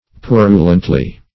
Purulently \Pu"ru*lent*ly\